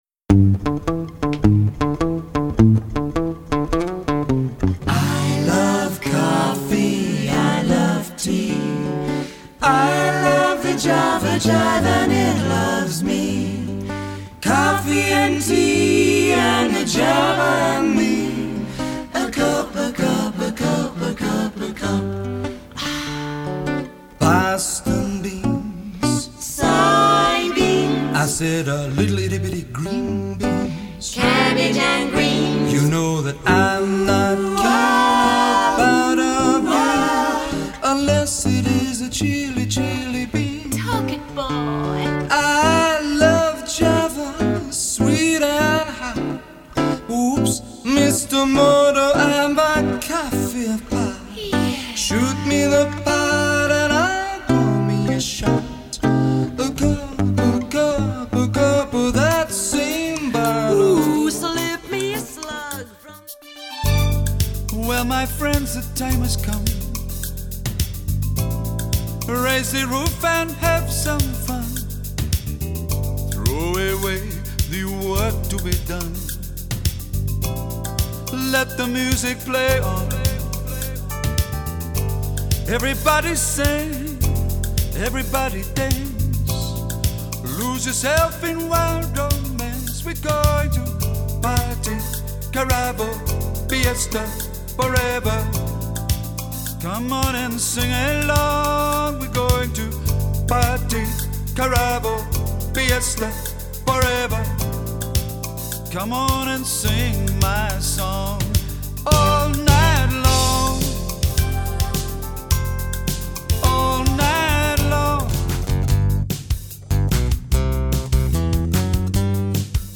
A vocalist performing with backtracks, acoustic guitar & banjolele (a cross between a banjo and a ukelele)